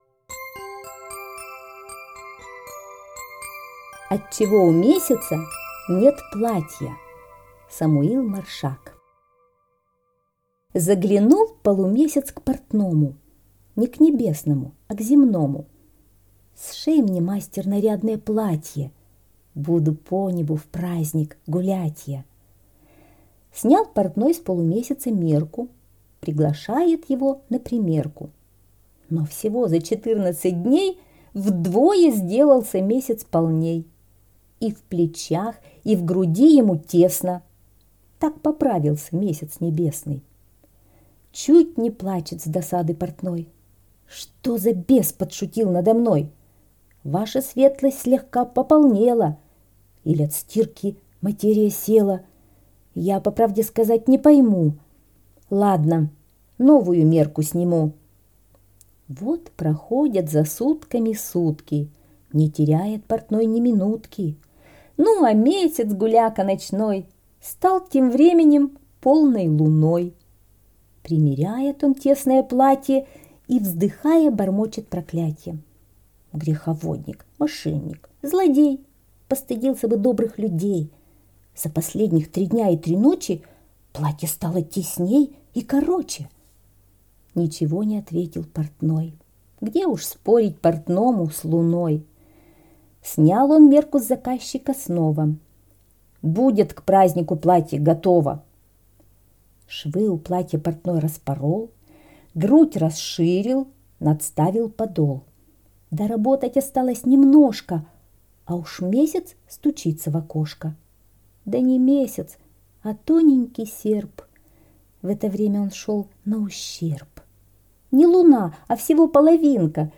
Слушать Отчего у месяца нет платья - аудиосказка Маршака С.Я. Сказка про то, как полумесяц заказал себе нарядное платье у портного.